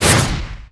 effect_wind_0005.wav